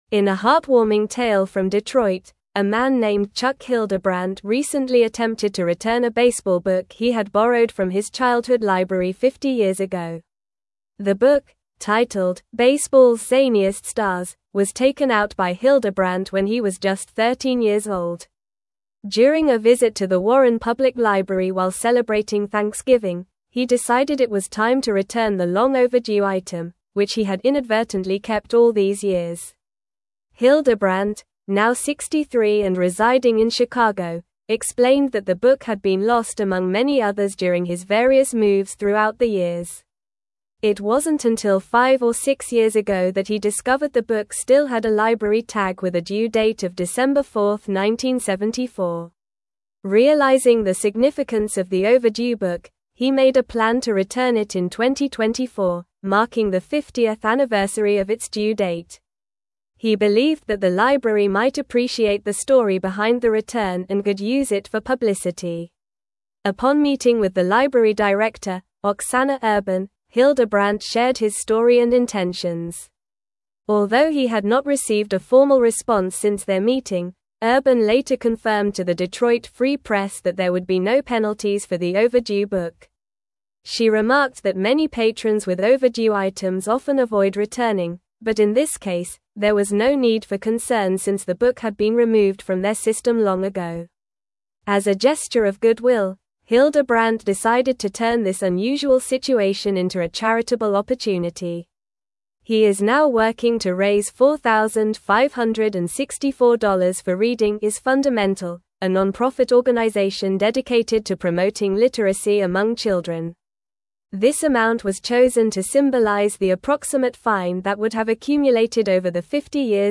Normal
English-Newsroom-Advanced-NORMAL-Reading-Man-Returns-Overdue-Library-Book-After-50-Years.mp3